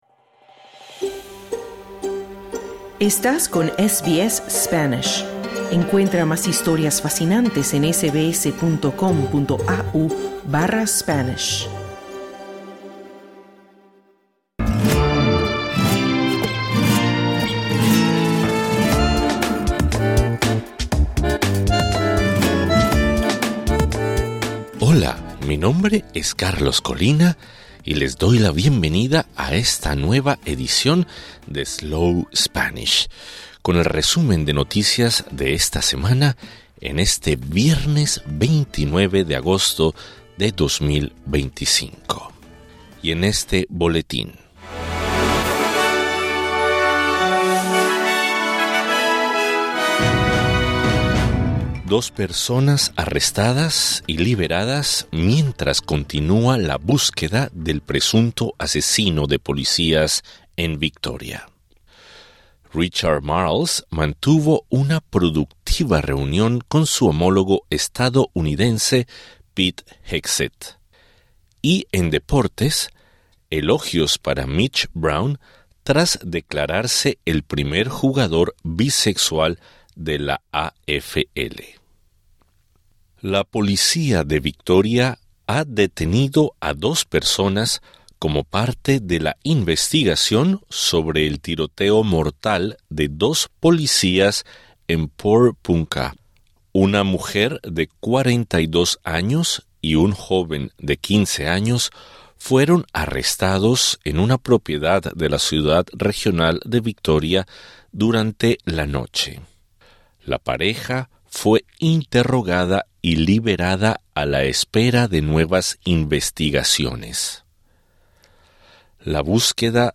Slow Spanish | Weekly news flash | 29 August 2025
Make SBS Slow Spanish a part of your tool kit for learning easy Spanish. This is our weekly news flash for the 29 of August 2025.